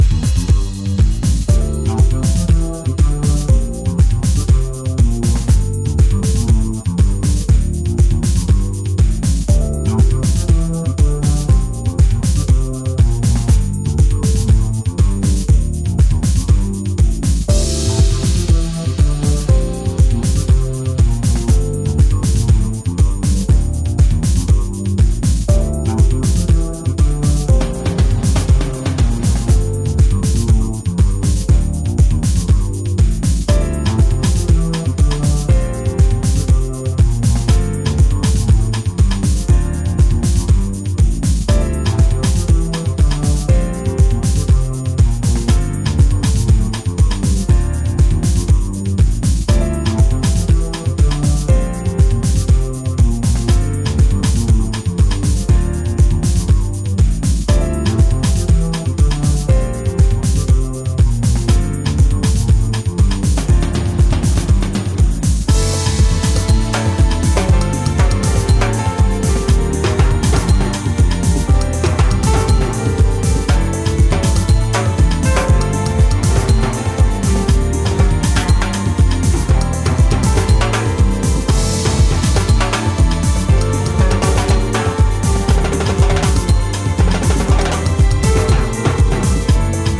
音質も良好、長く楽しめそうなタイプの大推薦盤です！！
ジャンル(スタイル) DEEP HOUSE